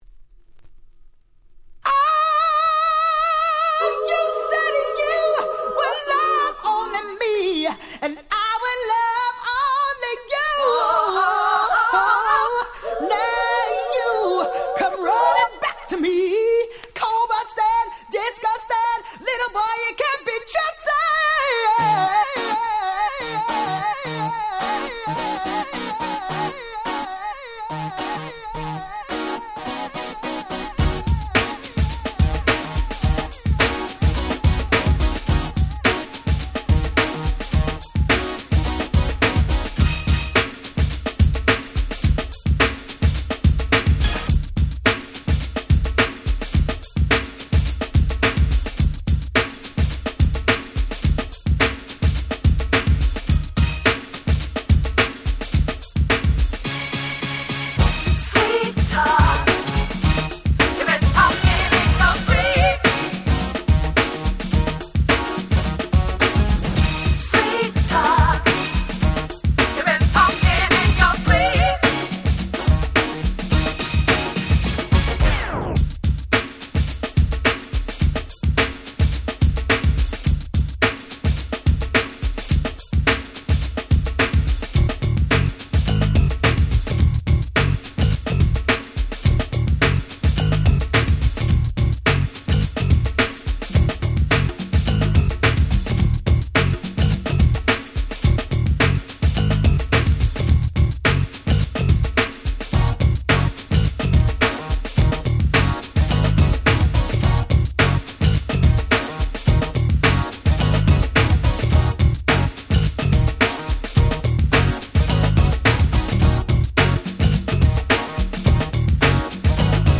軽快なHip Hop Beatにエモーショナルな女性Vocalが堪りません！！
ヒップホップソウル